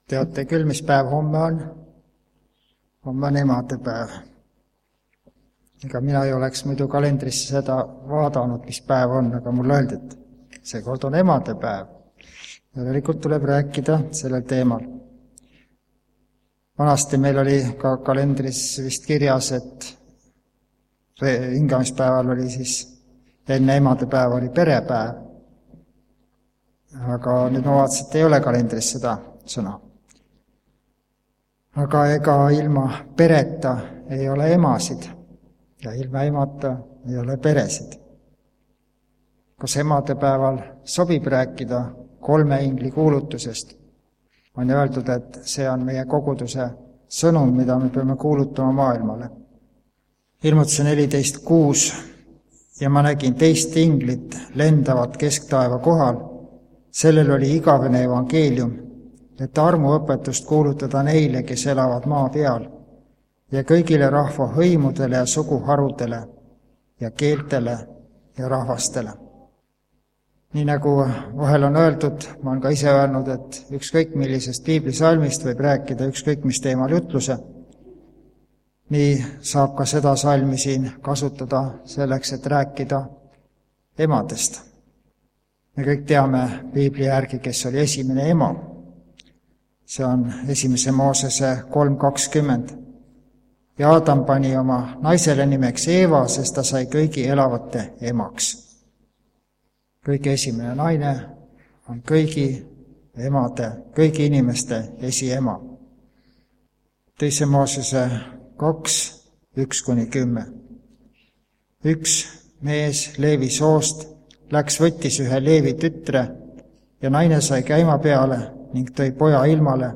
Koos laulame ka üldlaulu.